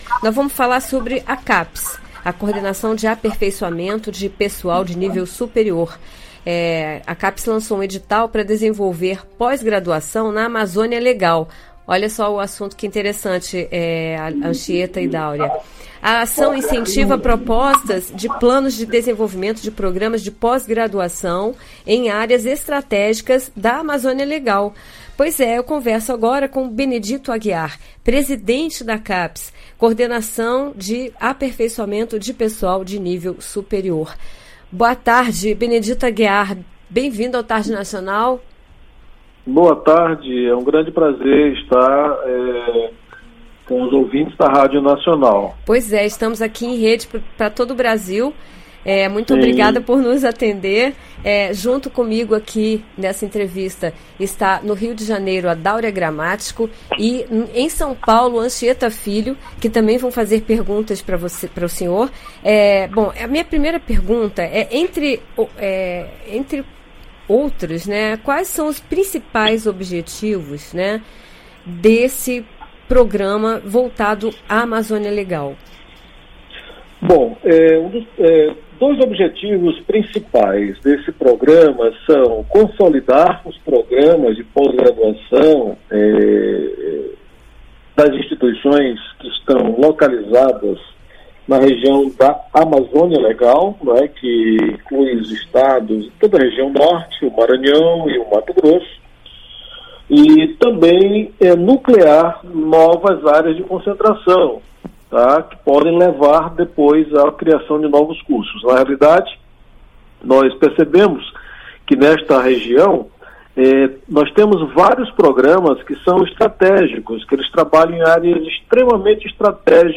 Capes lança edital para desenvolver pós-graduação na Amazônia Legal Ouça a entrevista com o presidente da Capes, Benedito Aguiar...